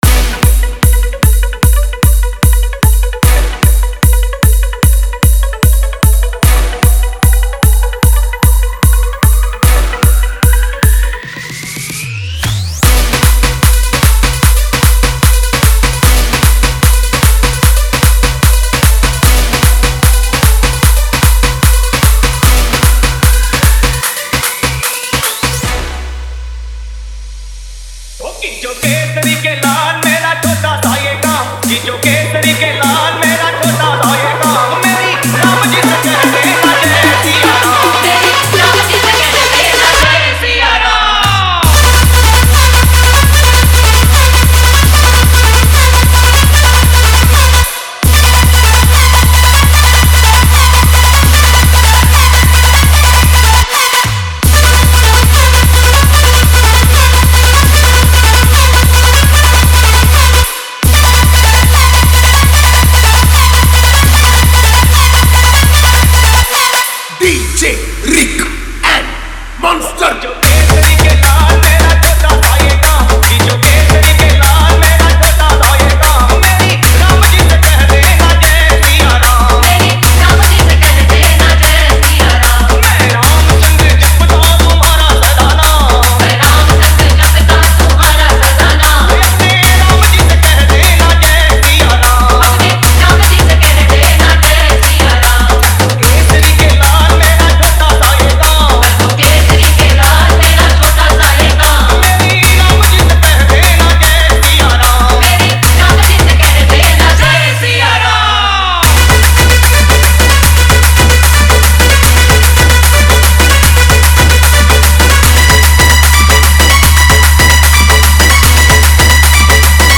- odia dj song